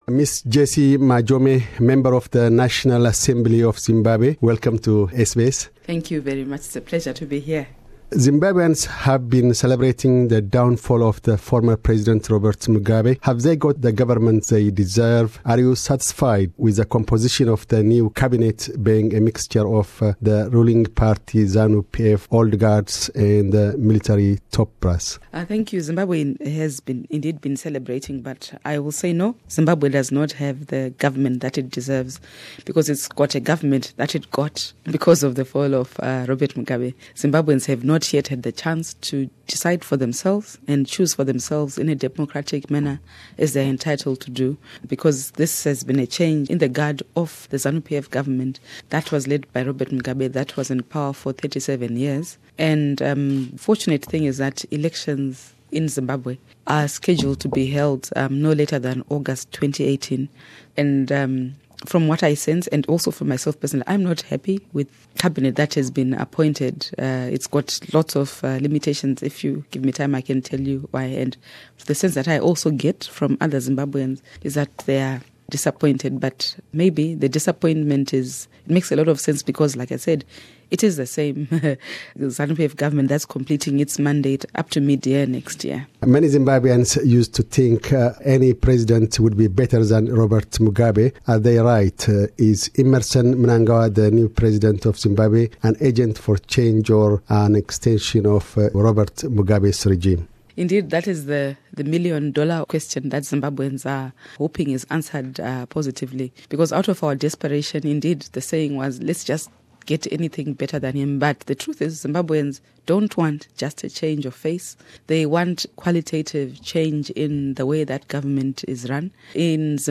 'Zimbabweans don’t want just a change of face' Zimbabwe Opposition MP says in Sydney